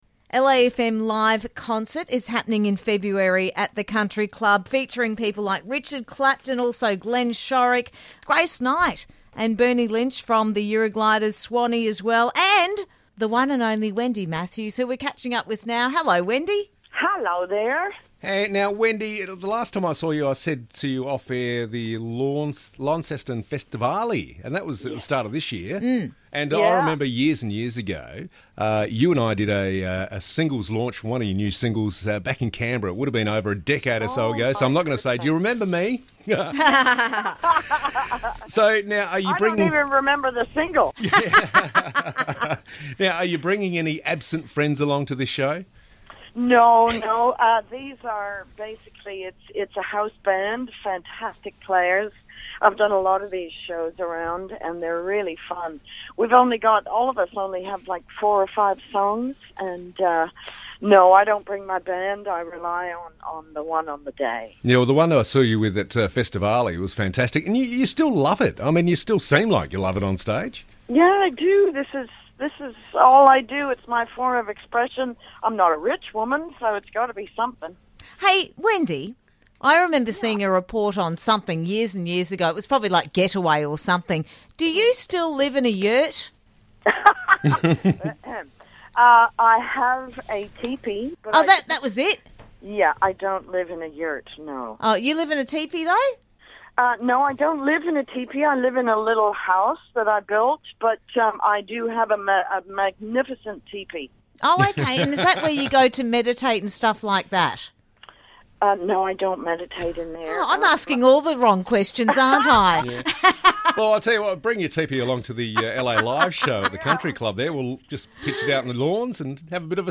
Interview - Wendy Matthews